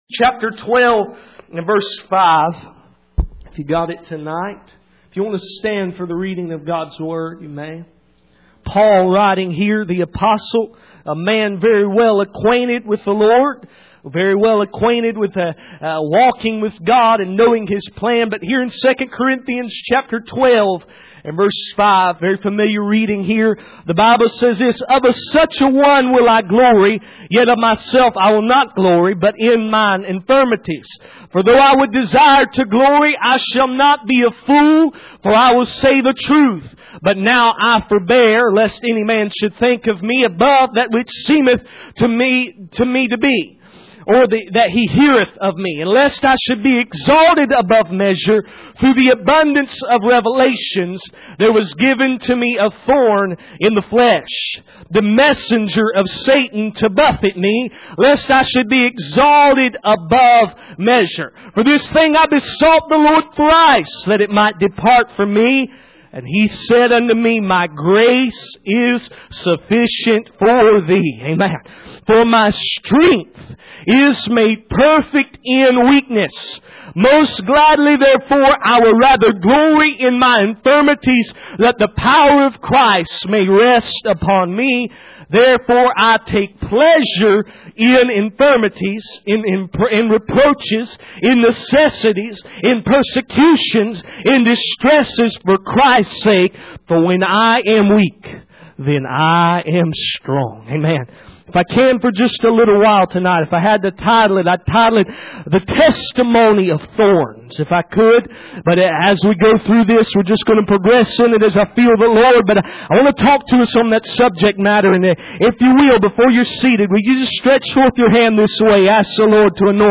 Passage: 2 Corinthians 12:5-5:10 Service Type: Sunday Evening